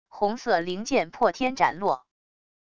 红色灵剑破天斩落wav音频